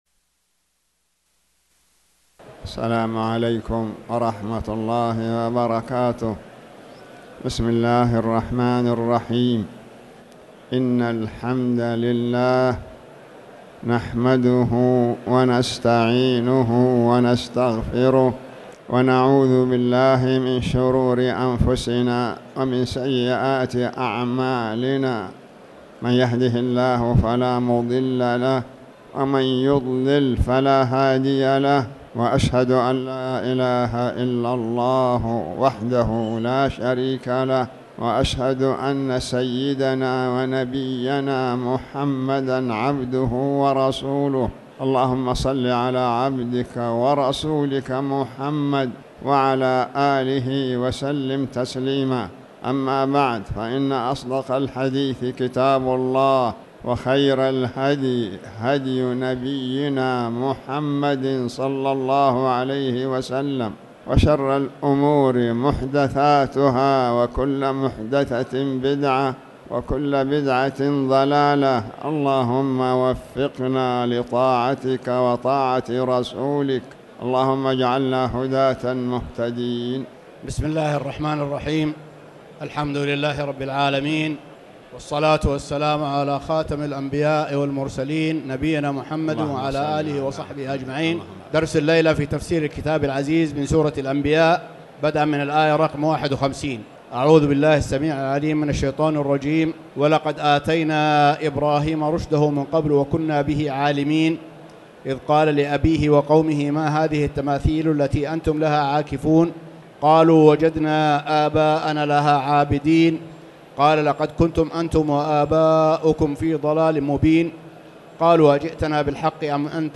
تاريخ النشر ١٠ صفر ١٤٣٩ هـ المكان: المسجد الحرام الشيخ